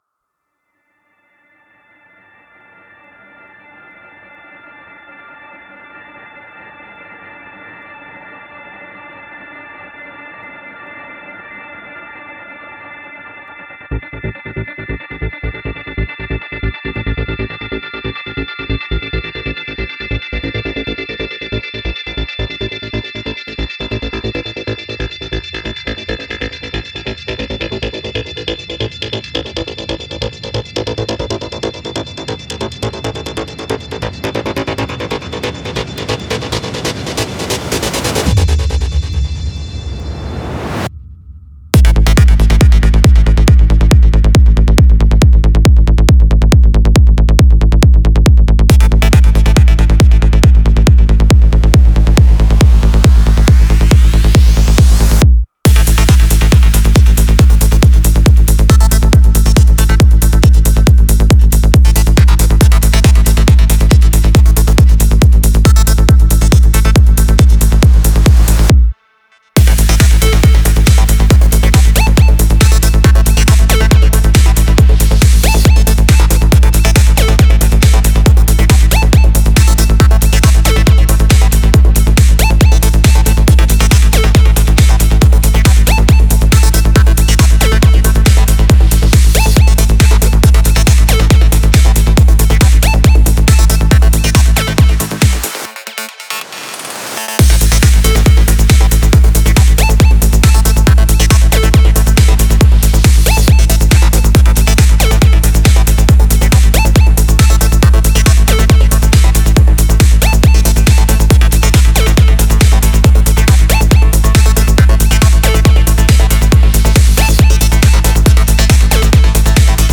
Я летом делал одному клиенту , псае подобное что ) (сводил ) такой же в принципе точно агрессивный сайдчейн делал ...оно без него вообще не качает Вложения Mixdown final.mp3 Mixdown final.mp3 12,8 MB · Просмотры: 1.555